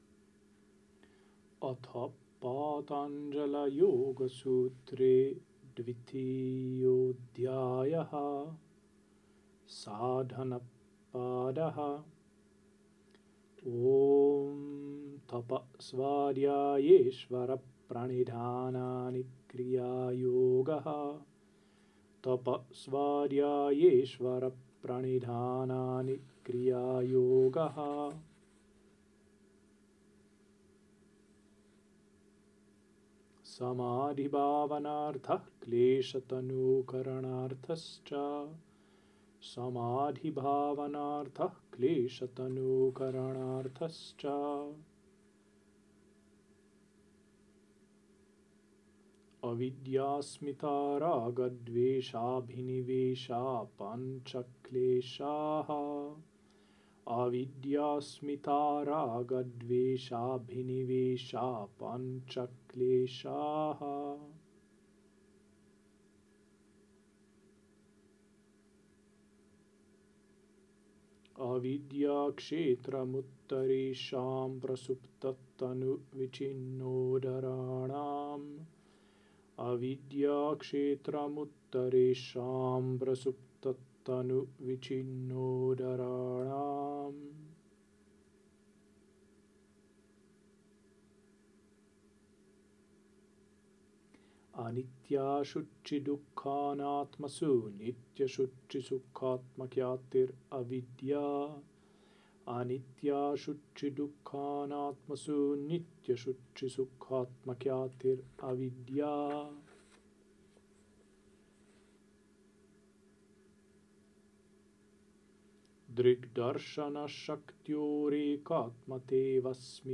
Toisen luvun sūtrat lausuttuna sanskritiksi